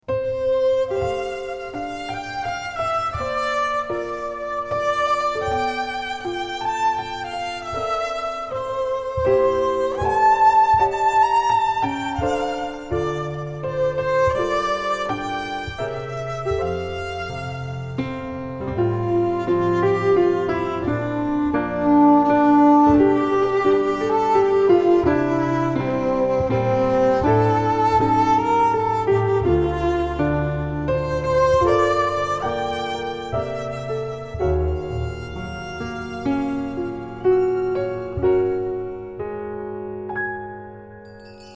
Genre : Ambient, New Age